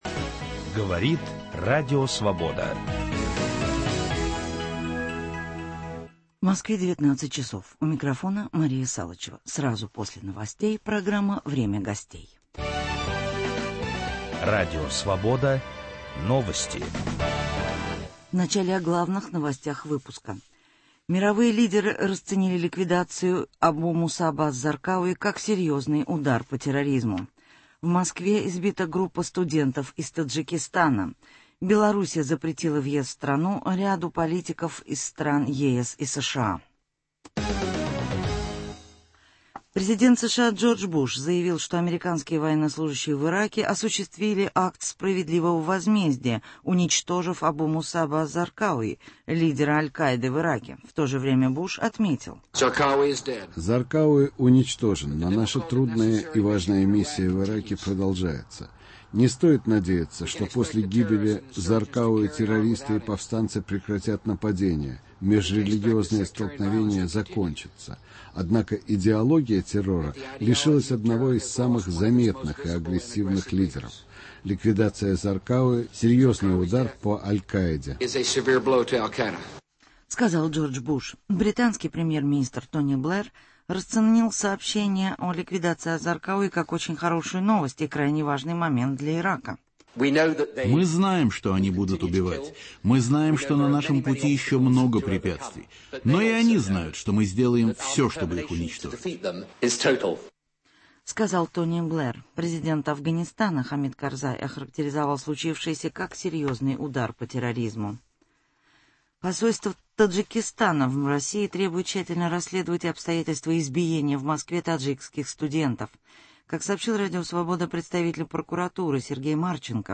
Итоги Международного газетного конгресса в Москве. В программе участвует декан факультета журналистики МГУ профессор Ясен Засурский.